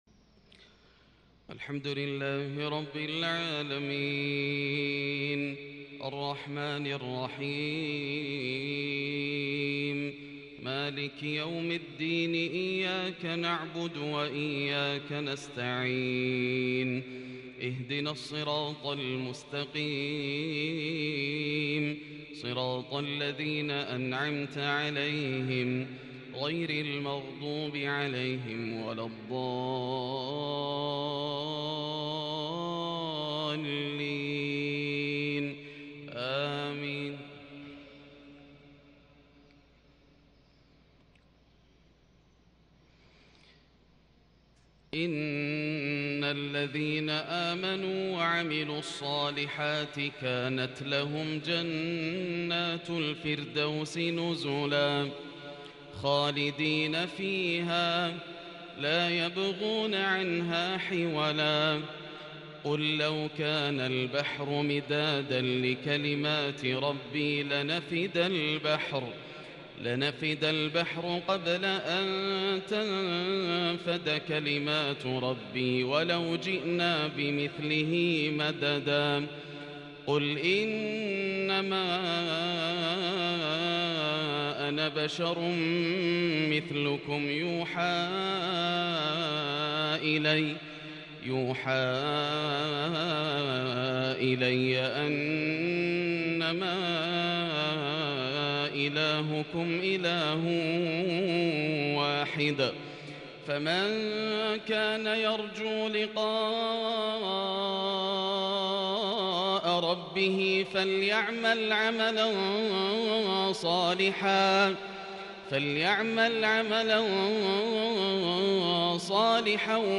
صلاة المغرب لخواتيم سورتي الكهف و مريم الخميس ٧-٢-١٤٤٢هـ | Maghrib prayer from Surat Al-Kahf and Surat Maryam 24/9/2020 > 1442 🕋 > الفروض - تلاوات الحرمين